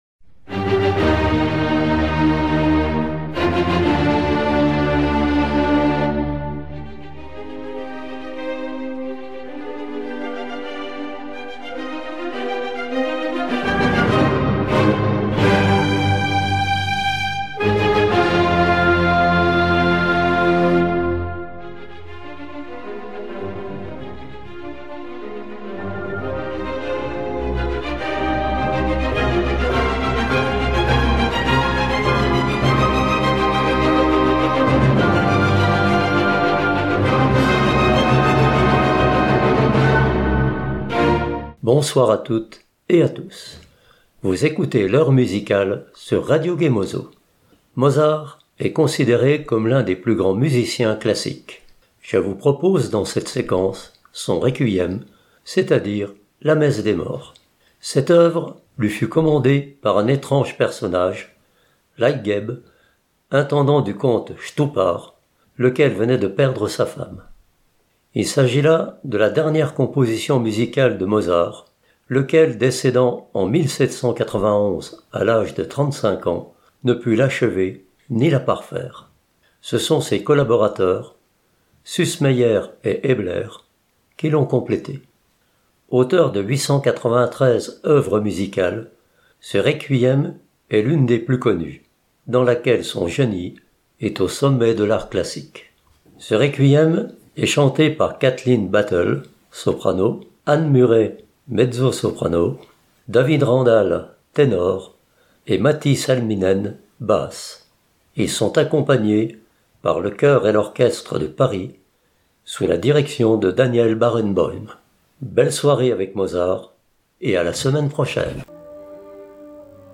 Entre airs sublimes, chœurs puissants et concertos envoûtants, découvrez comment ce compositeur du XVIIIᵉ siècle a su allier émotion, virtuosité et splendeur baroque.